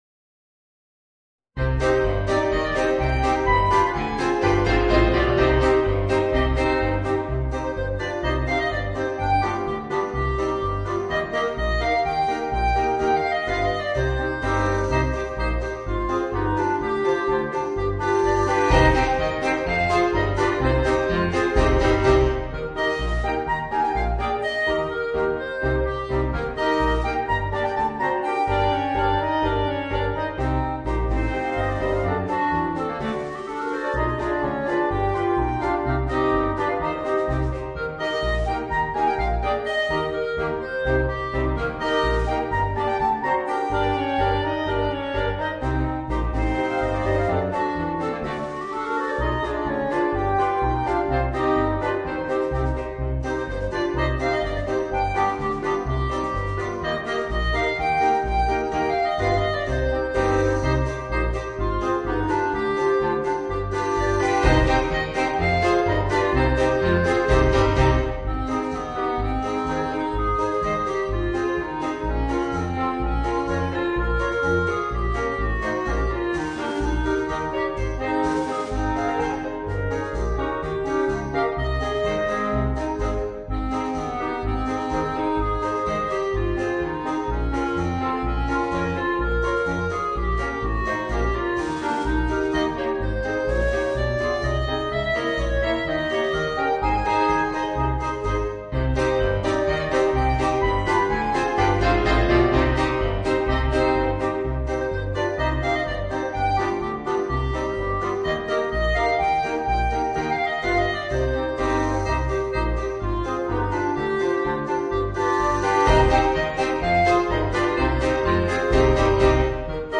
Voicing: 5 Clarinets and Rhythm Section